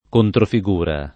[ kontrofi g2 ra ]